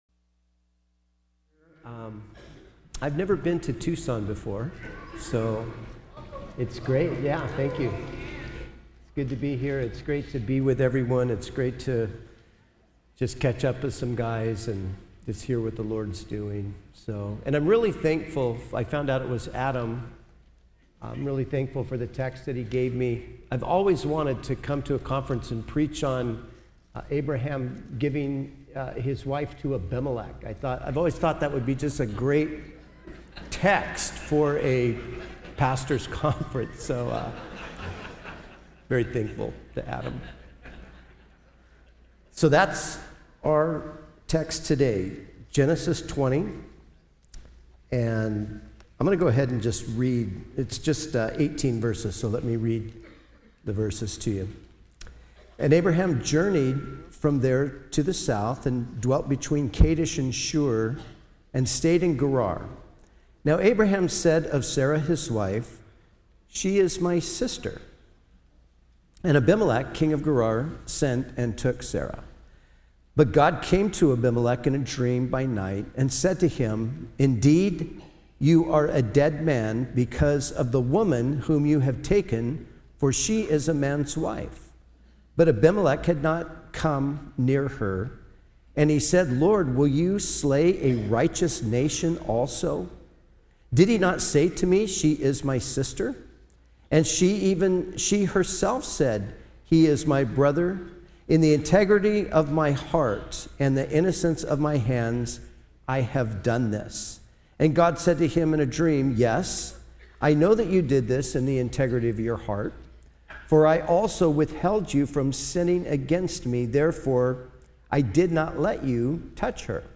at the 2015 SW Pastors and Leaders Conference